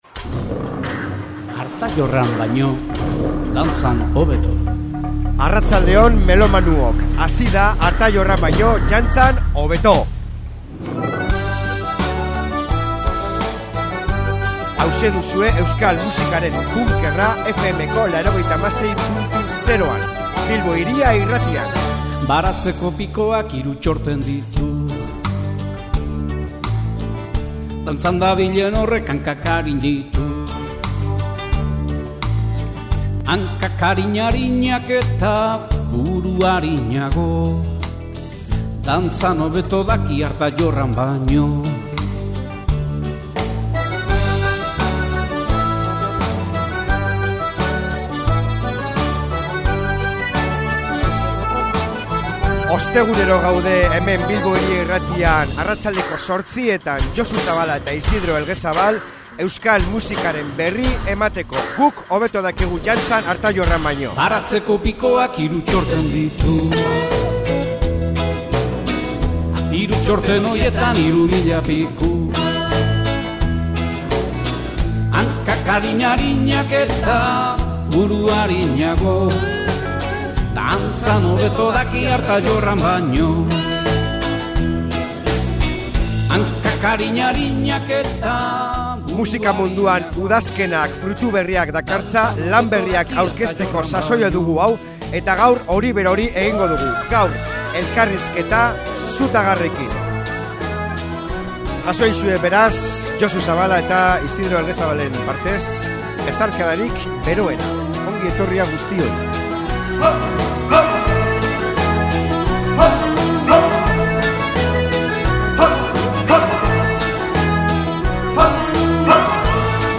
Elkarrizketa luze- zabala!